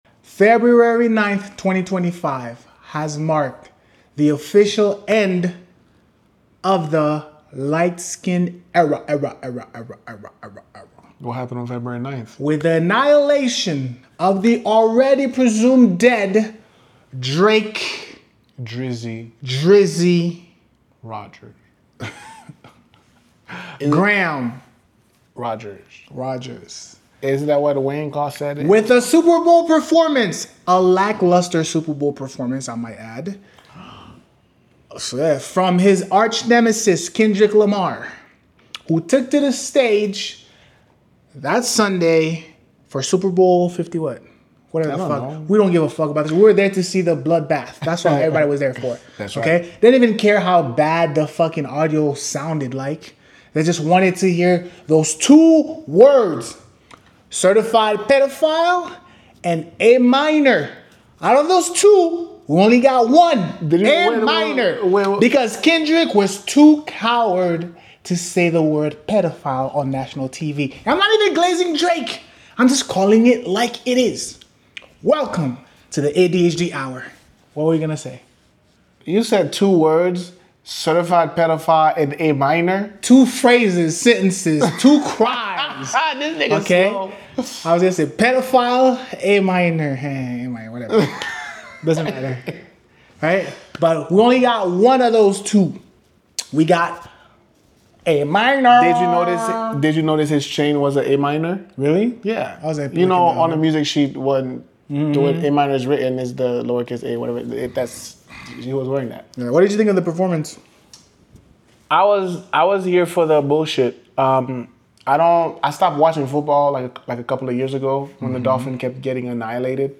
The twins discuss Kendrick Lamar. instagram: youtube: